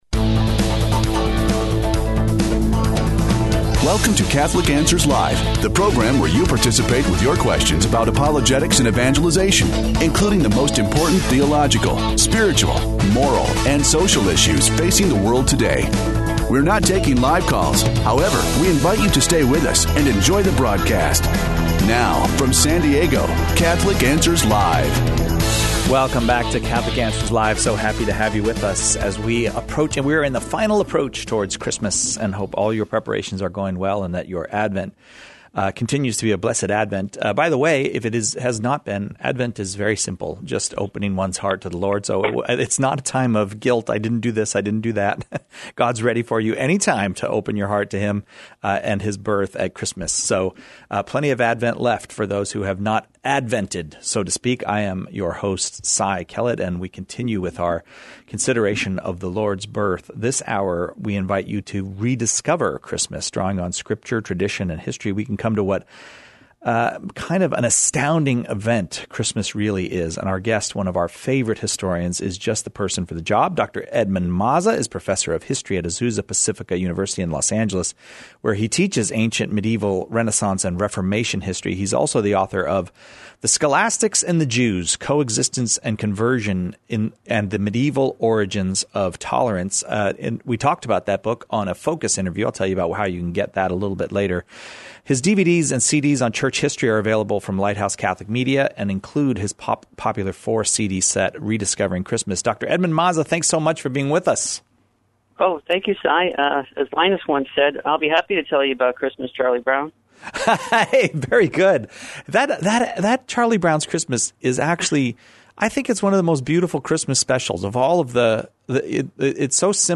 Rediscovering Christmas (Pre-recorded)